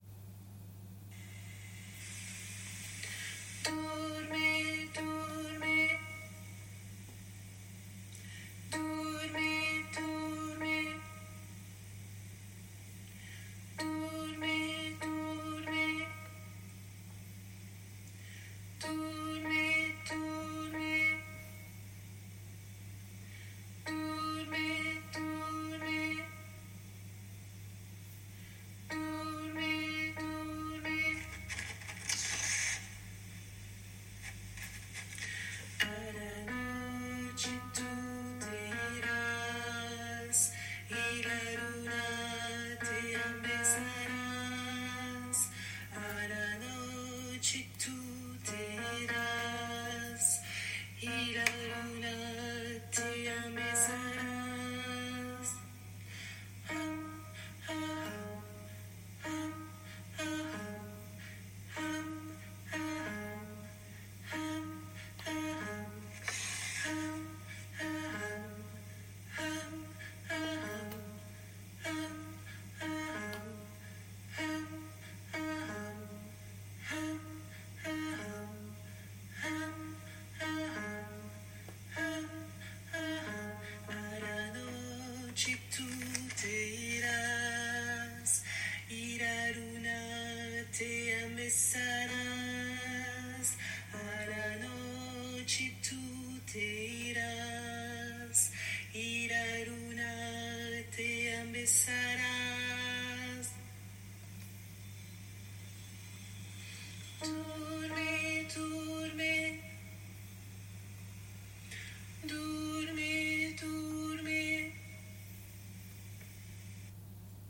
- berceuses juive séfarade
MP3 versions chantées
Alto